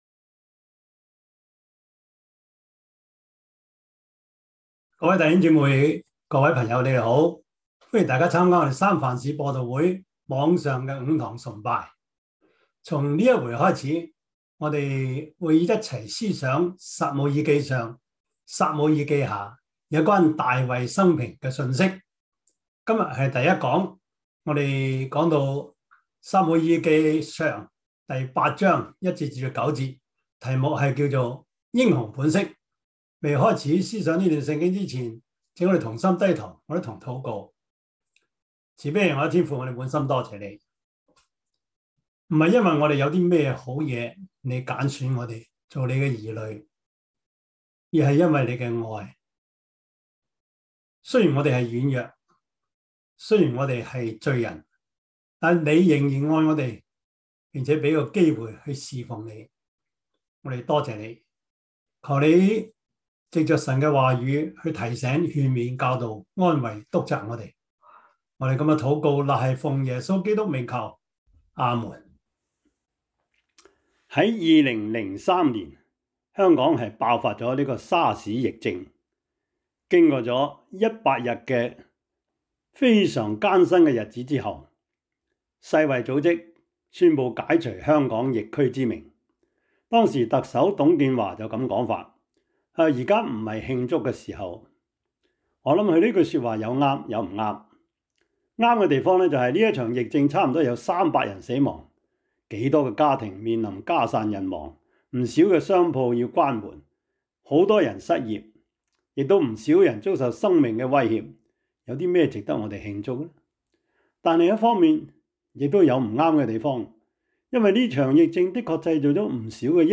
撒母耳記上 8:1-9 Service Type: 主日崇拜 撒母耳記上 8:1-9 Chinese Union Version
Topics: 主日證道 « 第四十三課: 神靈之手與長征 溪水流乾的河道 »